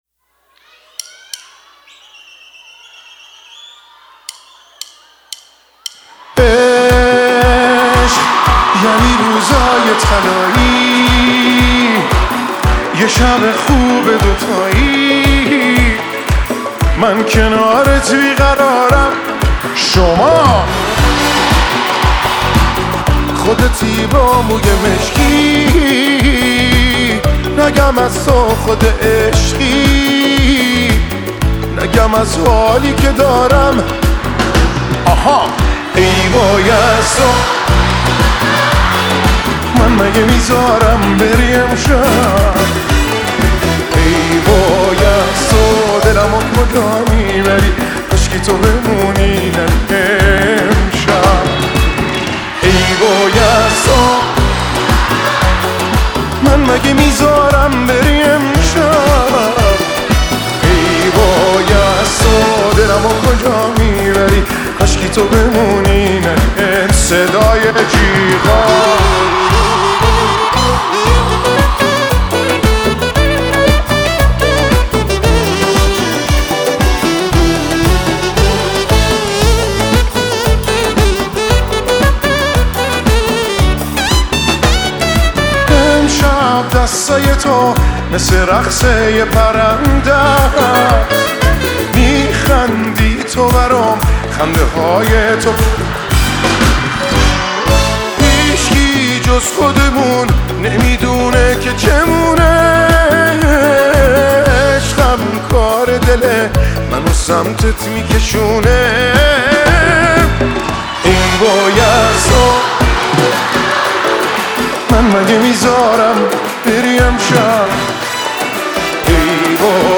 (Live Version)